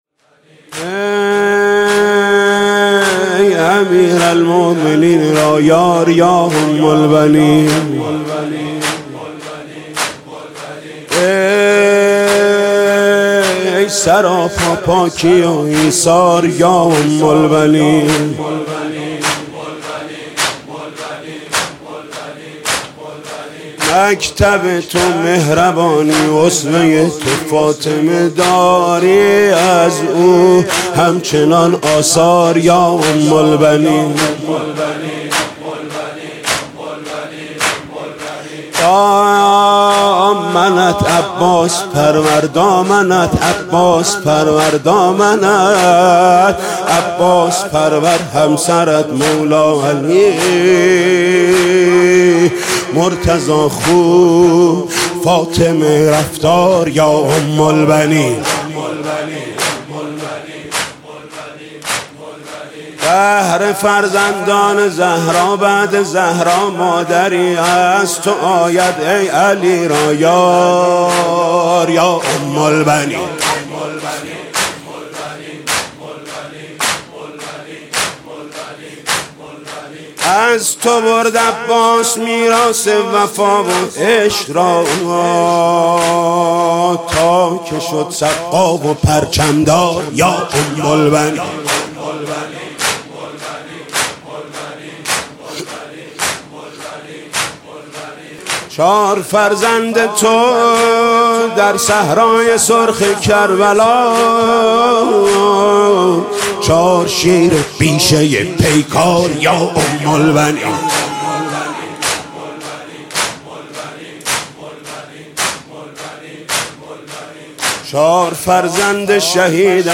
زمینه: ای امیرالمؤمنین را یار یا ام البنین